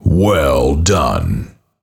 Фразы после убийства противника
WellDone.mp3